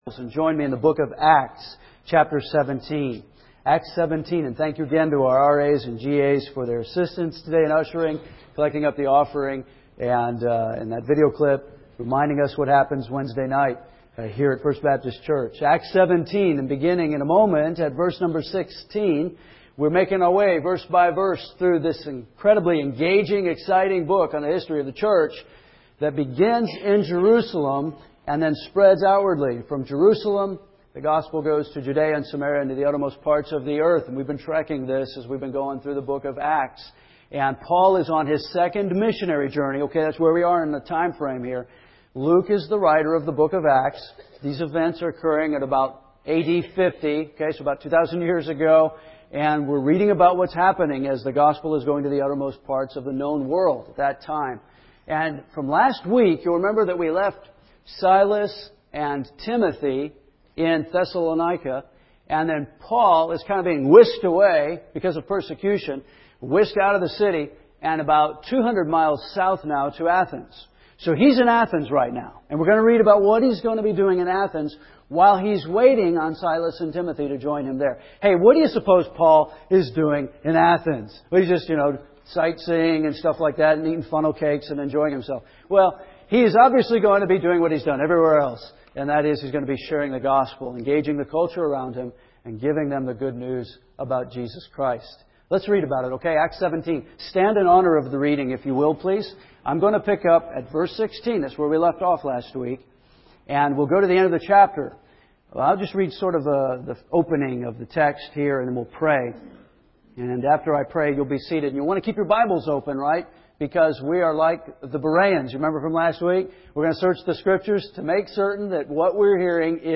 First Baptist Church Henderson, KY